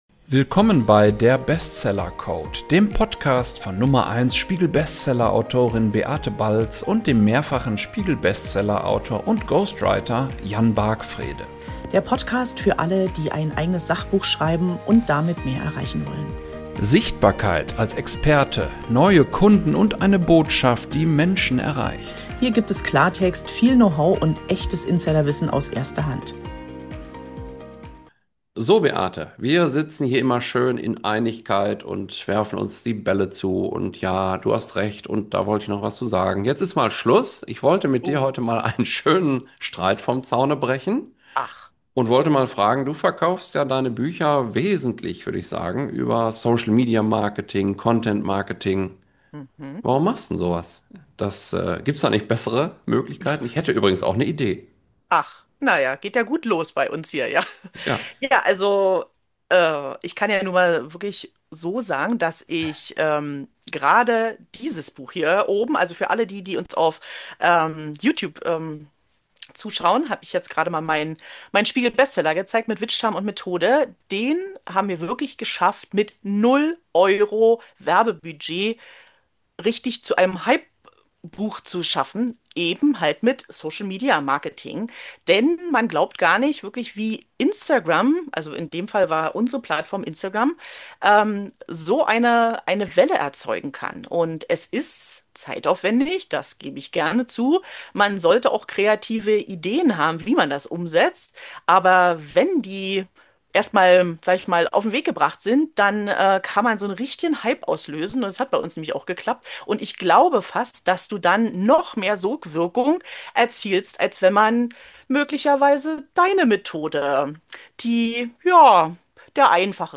Der Bestseller-Code # 11: Cash oder Content – Das Streitgespräch ~ Der Bestseller-Code Podcast